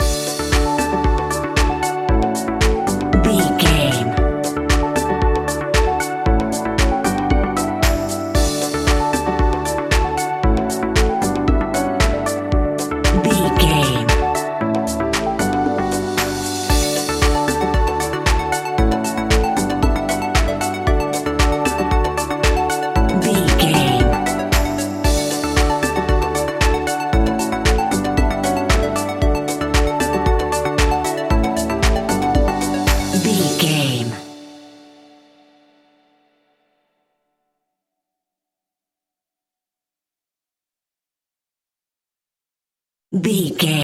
Ionian/Major
D
groovy
energetic
uplifting
futuristic
hypnotic
drum machine
synthesiser
piano
house
electro house
funky house
synth leads
synth bass